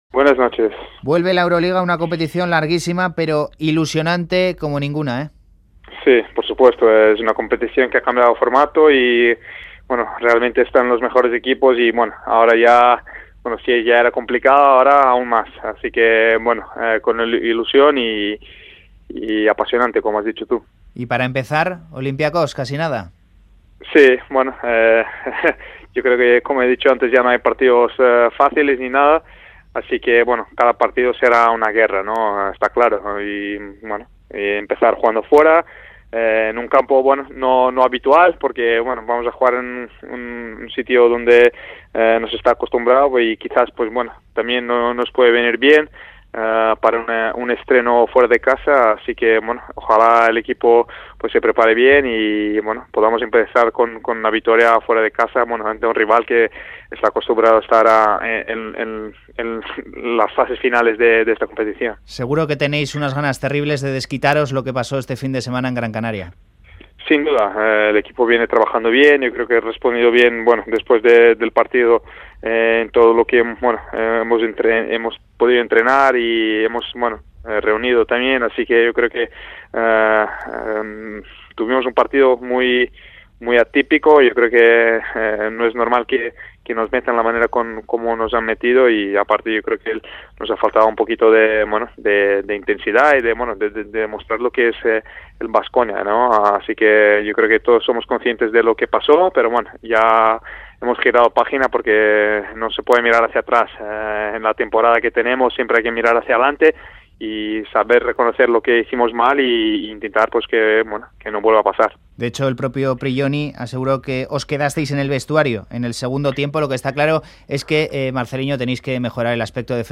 Audio: El base brasileño del Baskonia reflexiona sobre la actualidad del equipo en la previa de comenzar la Euroliga.